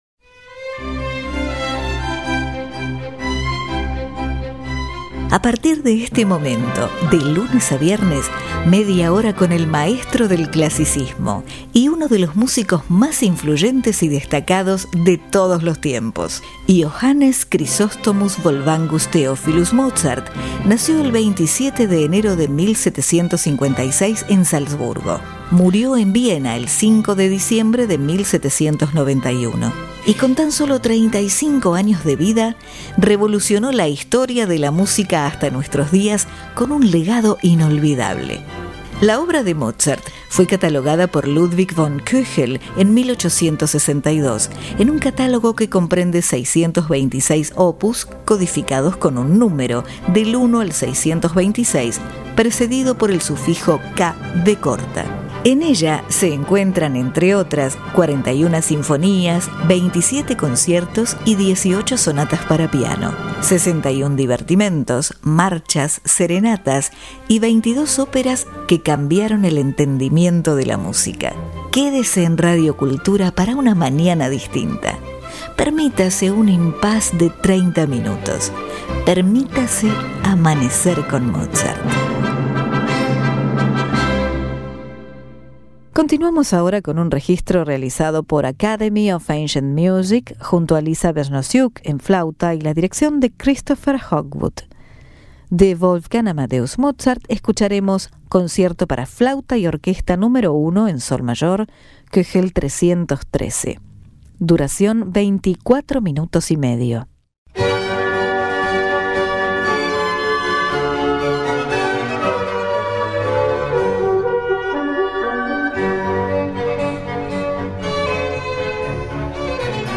Academy Of Ancient Music Christopher Hogwood